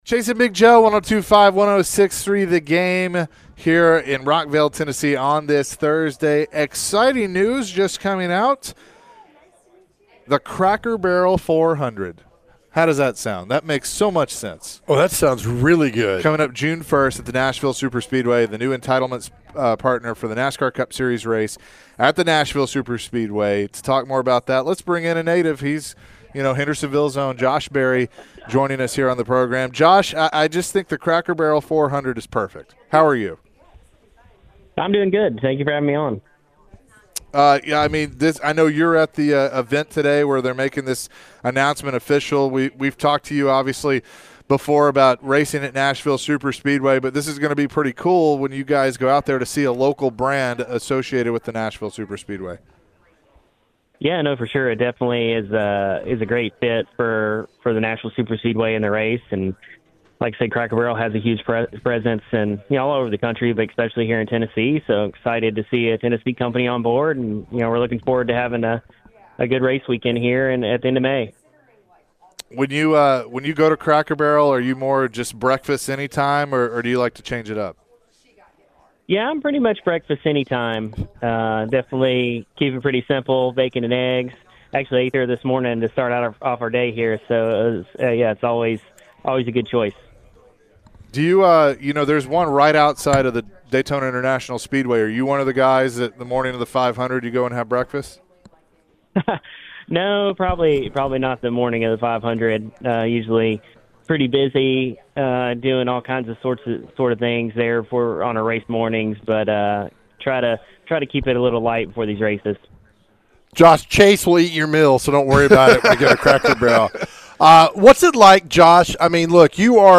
NASCAR Driver Josh Berry joined the show discussing the upcoming race and the Cracker Barrel 400.